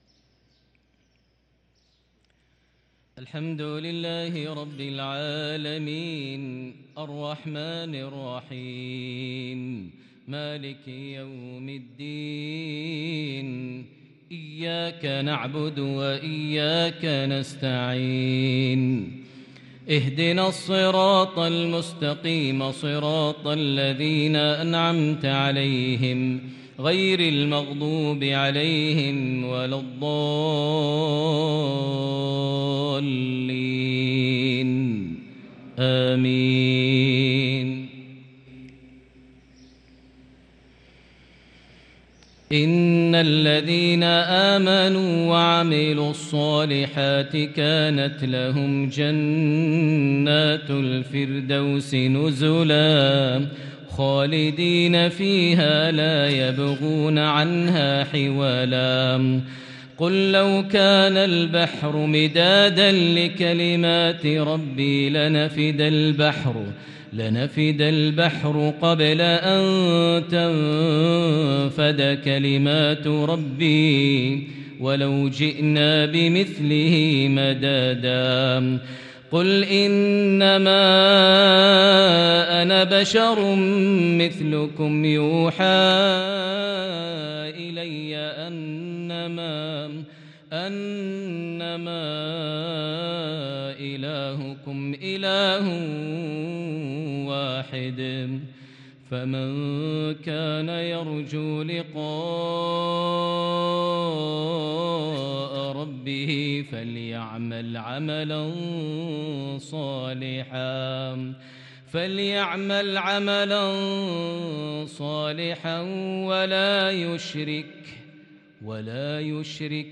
صلاة المغرب للقارئ ماهر المعيقلي 25 ربيع الأول 1444 هـ